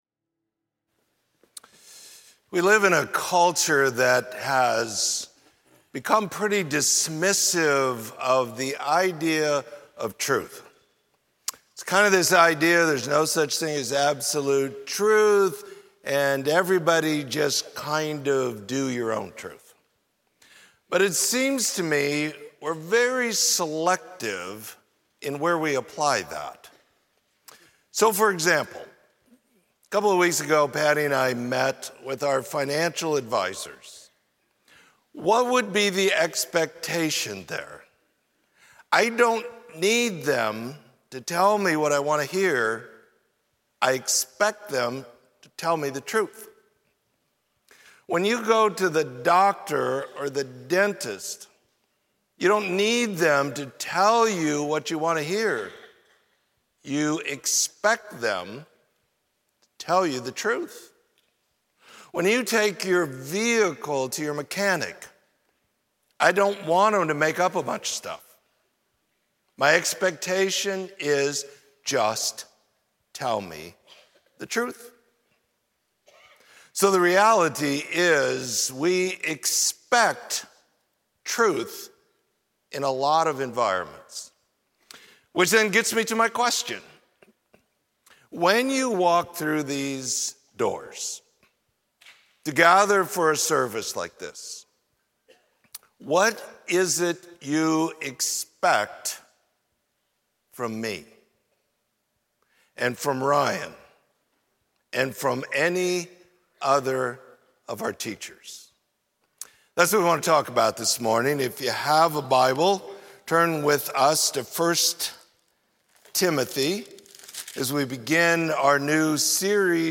Sermon: Called to Tell the Truth